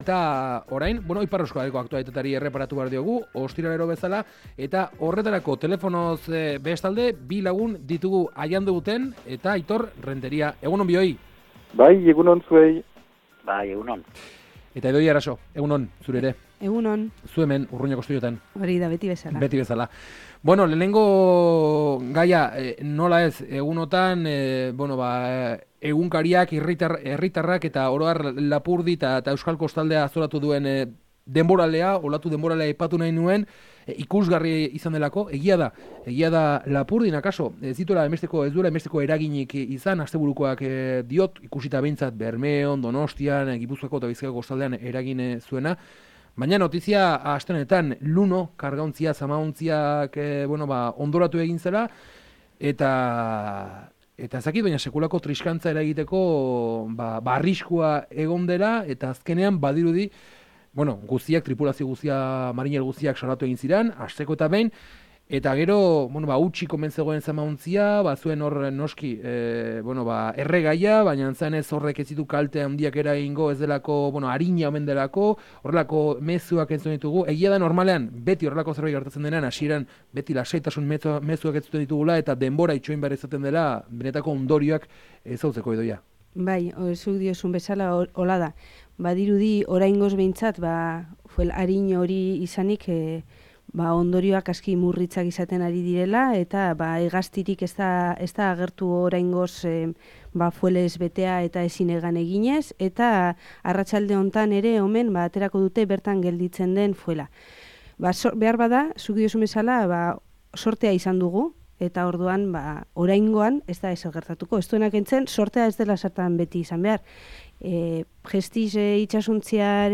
» Maskaradak eta “Luno” zamaontziaren ondoratzea hizpide mahai-inguruan - Info7 Irratia